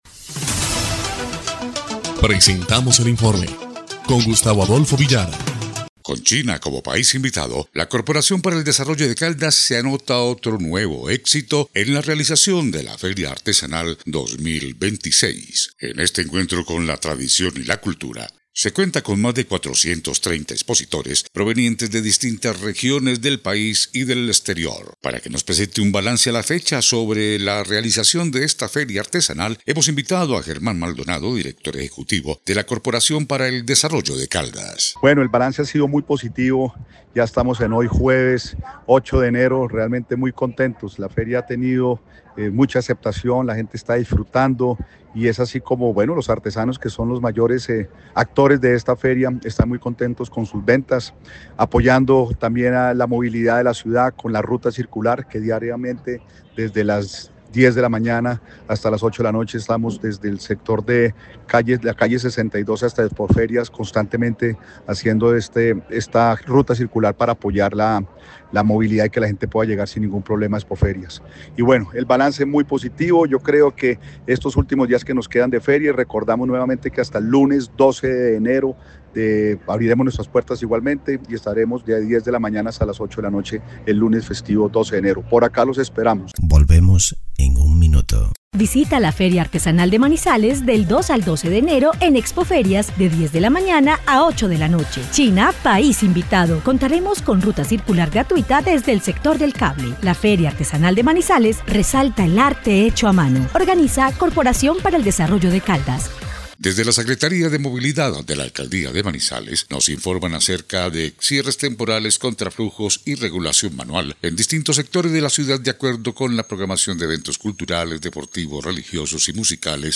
EL INFORME 3° Clip de Noticias del 9 de enero de 2026